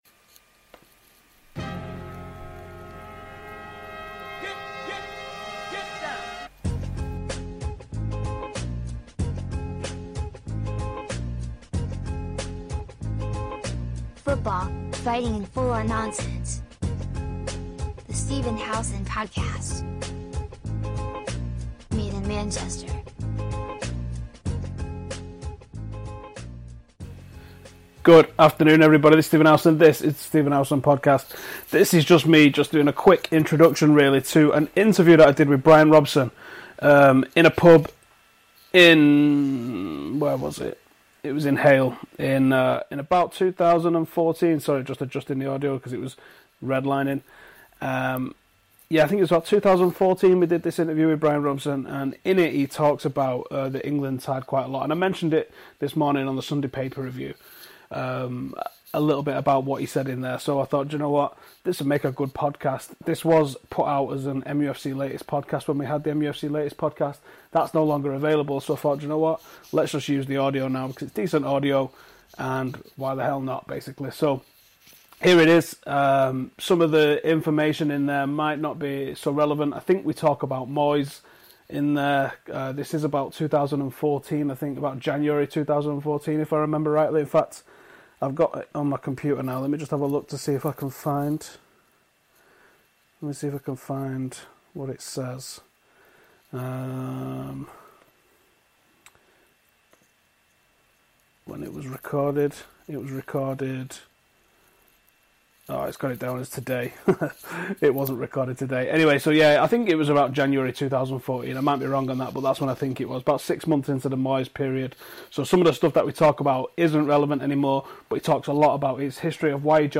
I met up with Captain Marvel, Bryan Robson a couple of years ago for a chat. He spoke at length about the England national team and I just remembered I had the audio.